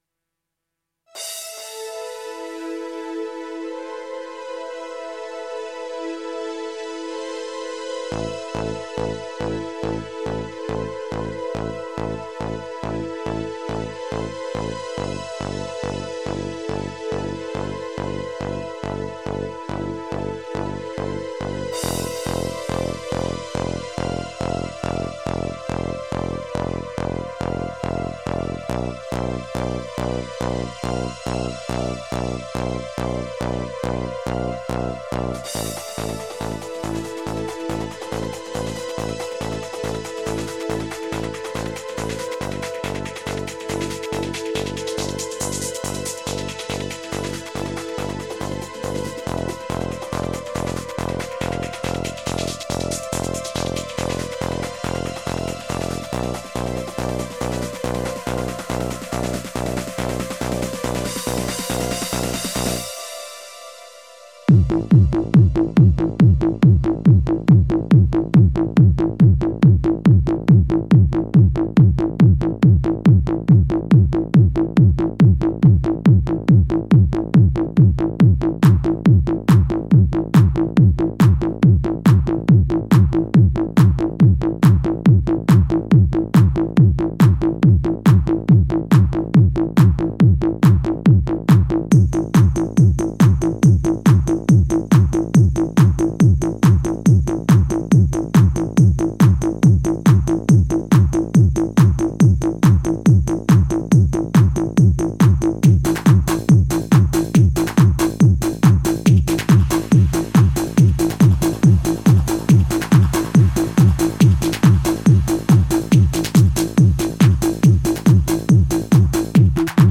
Жанр: Dream